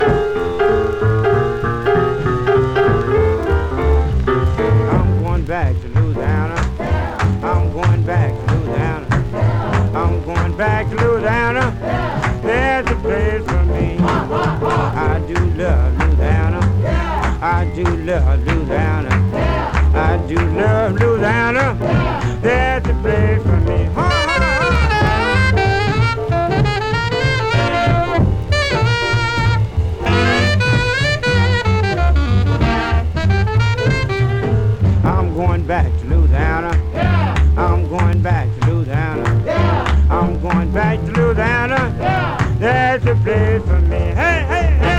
R&B, Blues, Jump, Louisiana　USA　12inchレコード　33rpm　Mono